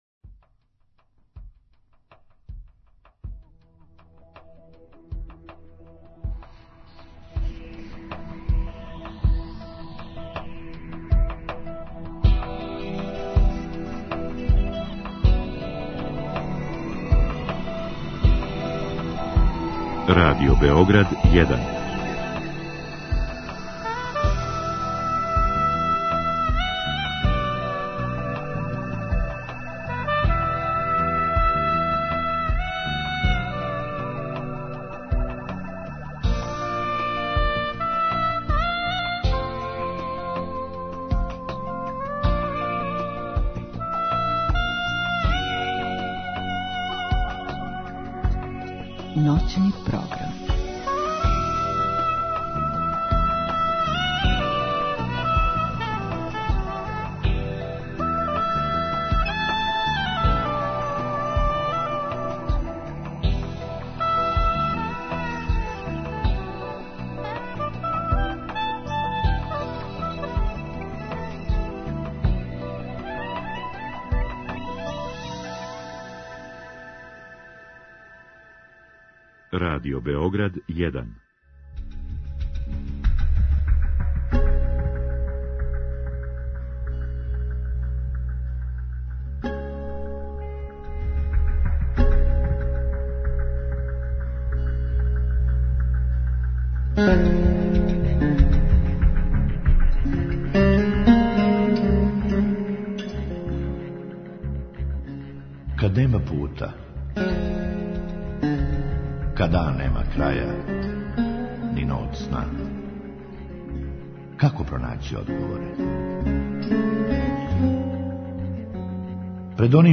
Други сат је резервисан за слушаоце, који у програму могу поставити питање гошћи.